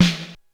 Index of /90_sSampleCDs/300 Drum Machines/Korg DSS-1/Drums03/01
Rimshot.wav